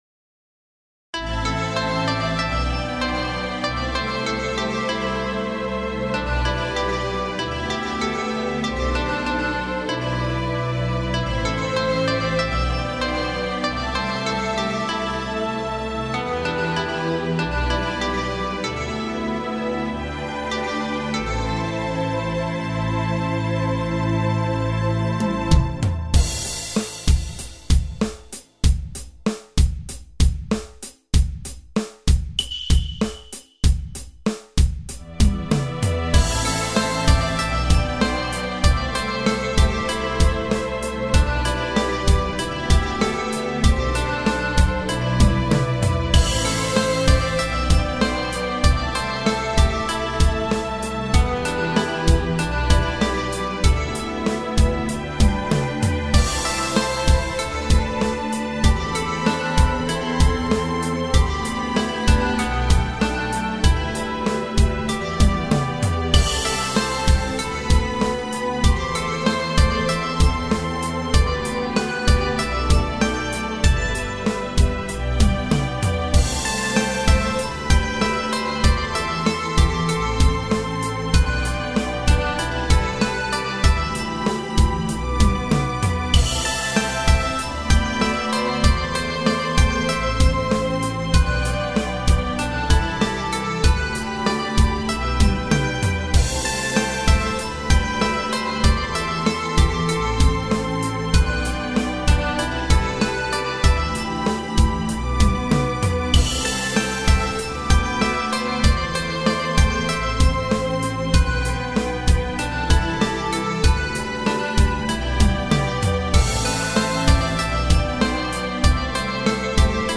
スローテンポロング暗い穏やか